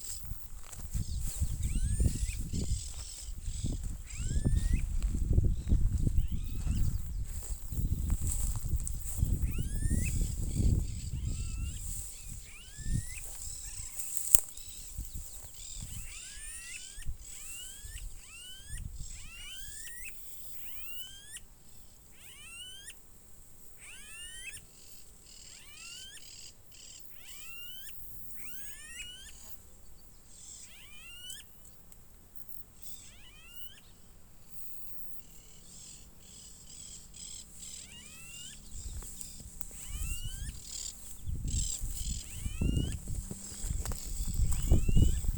Smooth-billed Ani (Crotophaga ani)
Detailed location: Costanera de Río Hondo
Condition: Wild
Certainty: Photographed, Recorded vocal